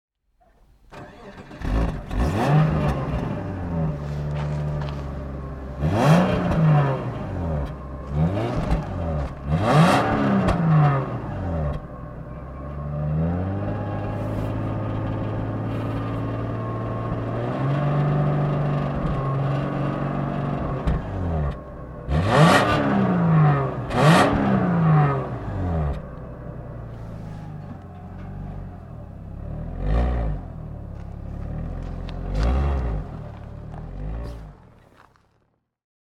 Subaru_SVX_1992.mp3